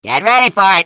Defective Turret voice lines - Portal Wiki
This is a complete list of the Defective Turret's voice lines from Portal 2.